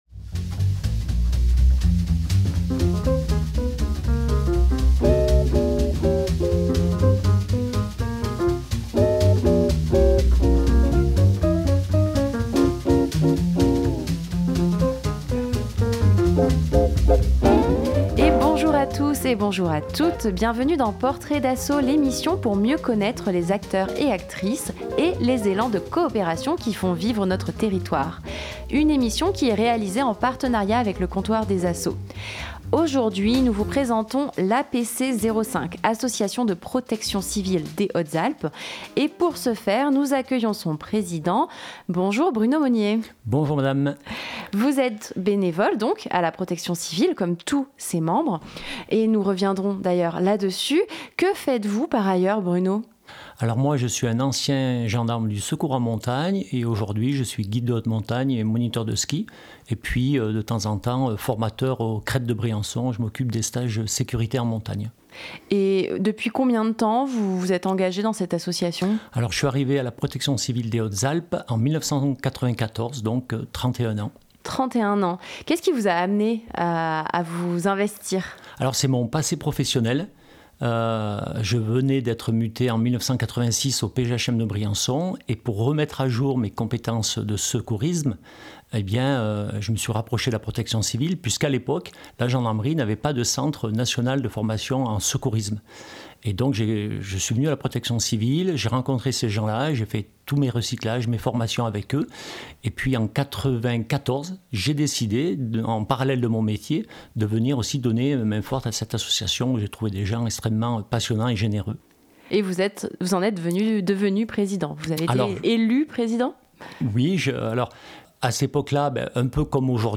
Tous les deux mois, dans Portraits d'assos, nous rencontrons une association du nord des Hautes-Alpes. Une émission pour mieux connaitre les acteurs et actrices de notre territoire, les démarches participatives et coopératives qui font vivre le réseau local.